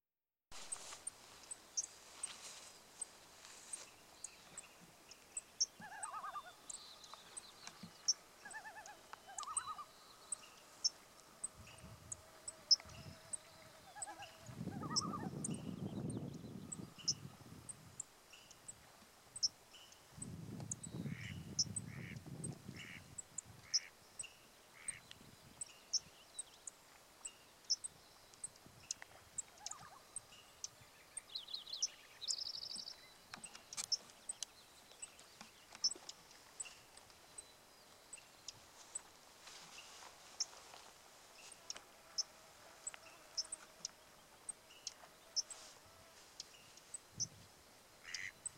Here’s our audio file with one of their calls.
Gosh, the Loon pair wail is beautifully spine-tingling – it reminds me of whale song.
loons-at-wolf-lake.wav